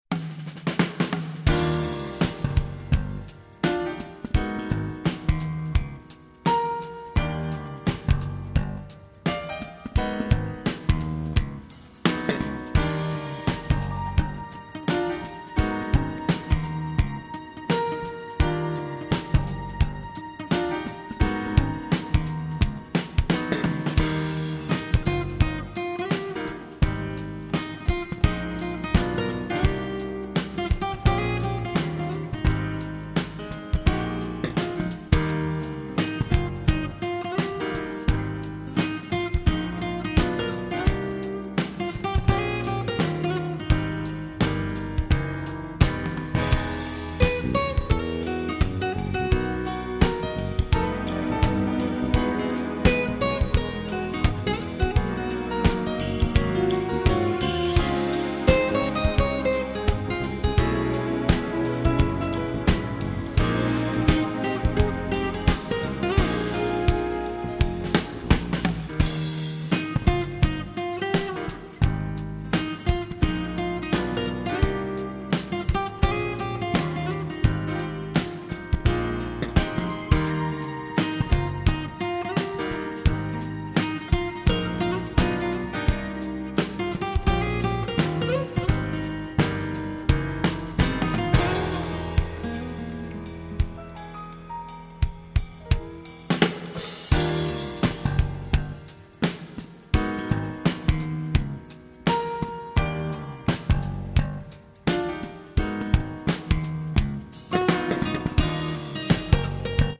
-   爵士及藍調 (298)
★ 精妙吉他演奏，震撼鼓聲與鋼琴，豐富聆賞效果！